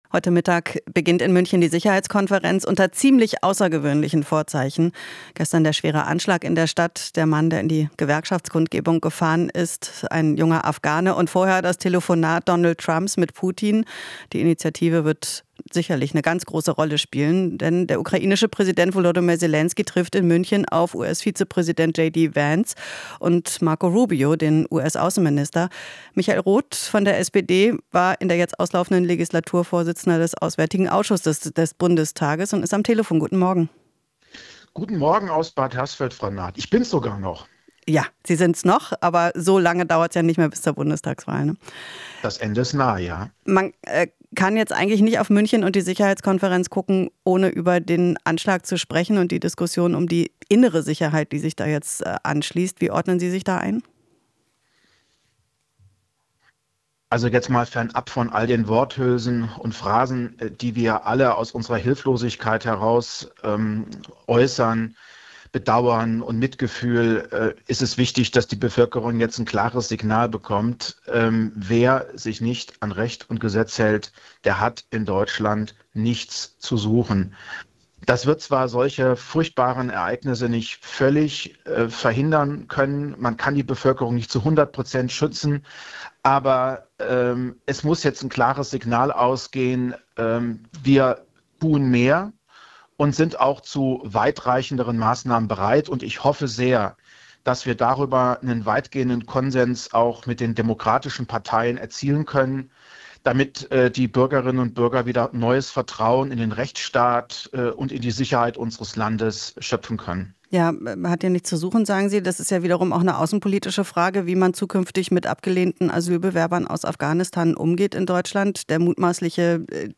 Interview - Roth (SPD): "Putin wird sich bestätigt fühlen"